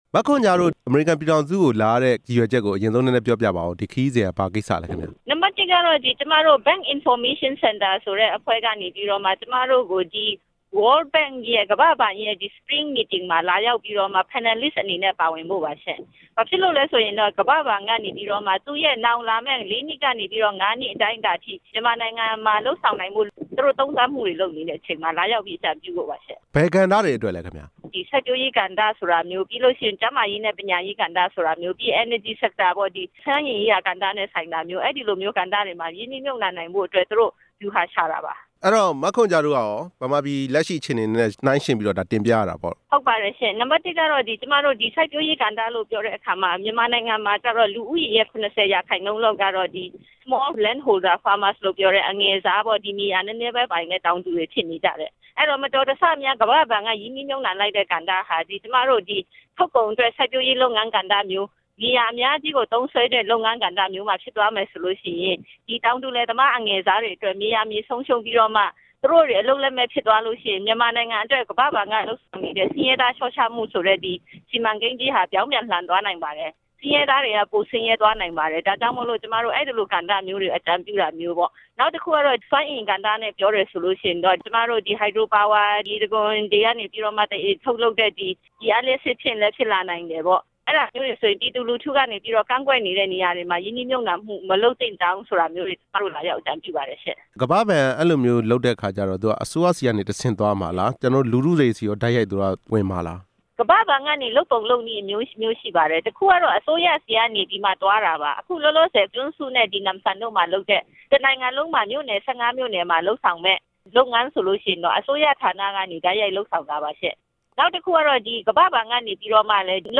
မြန်မာနိုင်ငံက ရင်းနှီးမြှုပ်နှံရေး စီမံကိန်းတွေနဲ့ ပတ်သက်လို့ မေးမြန်းချက်